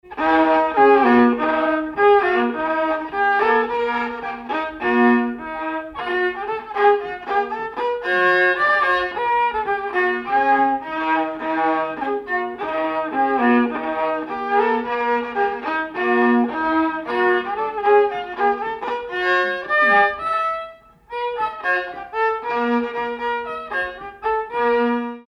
Polka
danse : polka
circonstance : bal, dancerie
Pièce musicale inédite